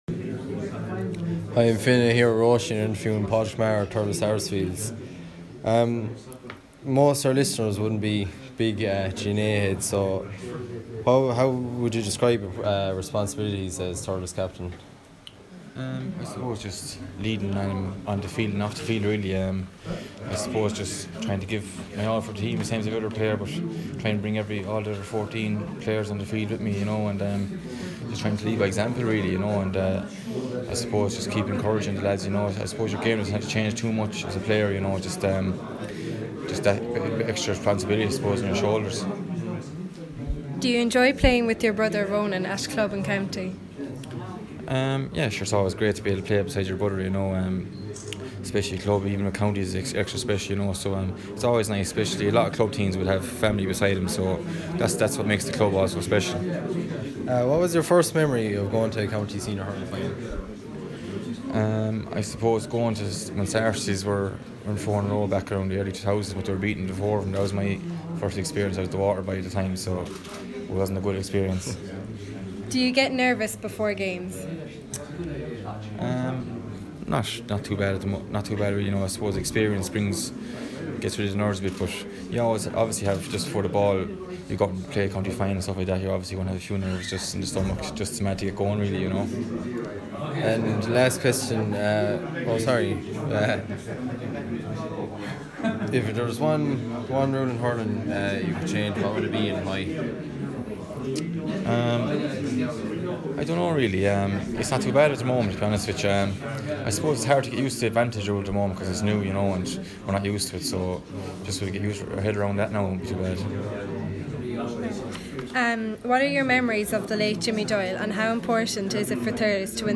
Interview Padraic Maher of Thurles Sarsfields